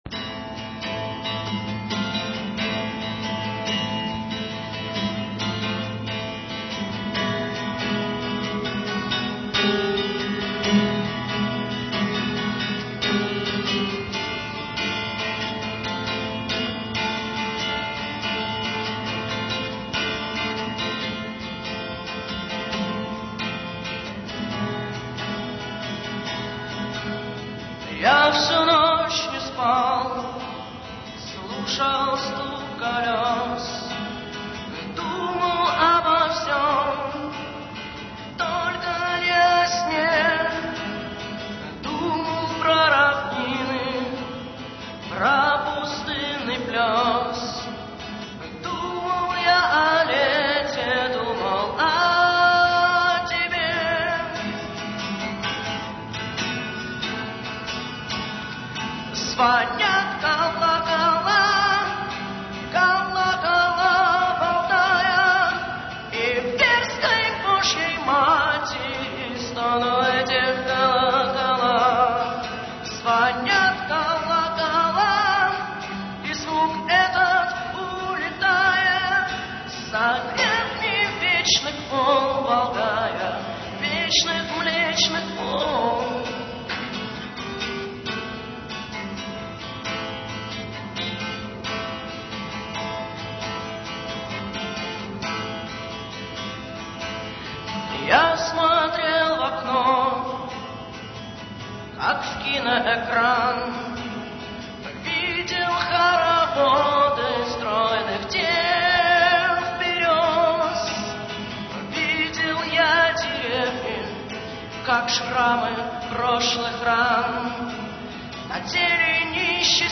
Бардовский концерт
декабрь 2000, Гимназия №45
665 kb, авторское исполнение